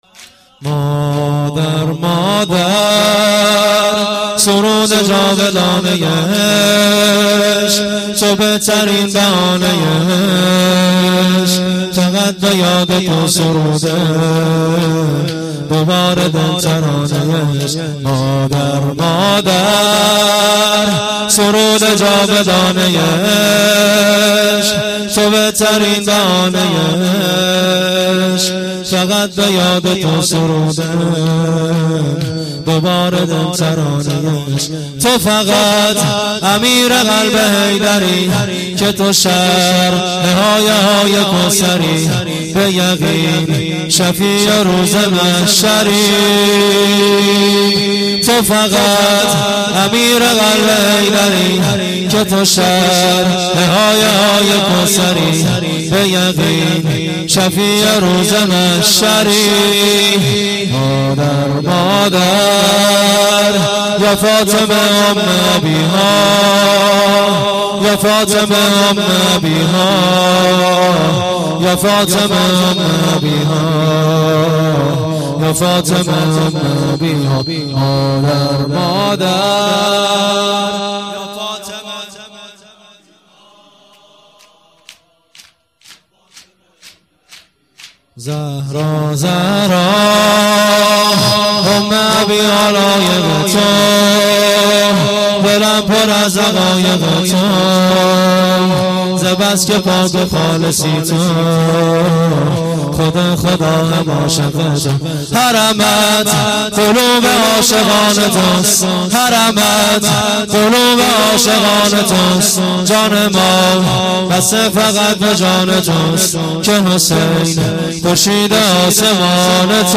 شور - مادر مادر